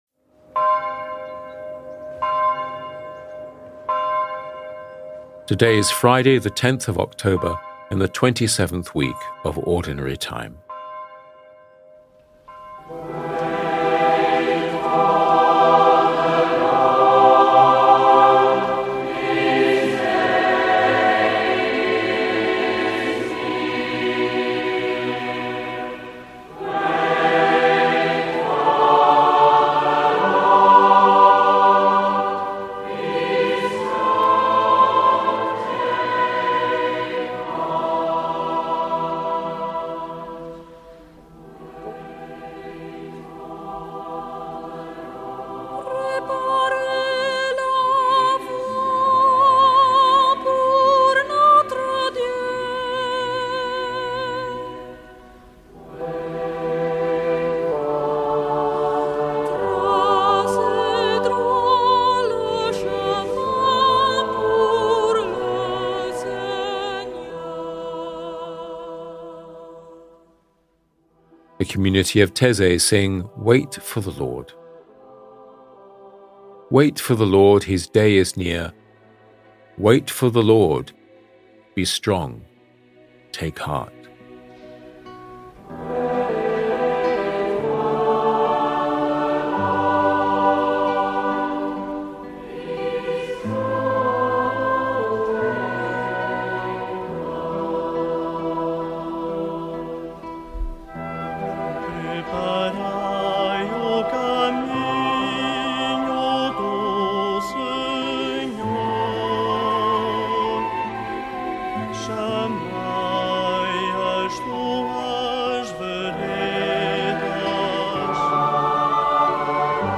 The community of Taizé sing Wait for the Lord.